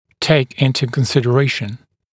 [teɪk ‘ɪntə kənˌsɪdə’reɪʃn][тэйк ‘интэ кэнˌсидэ’рэйшн]принимать во внимание, учитывать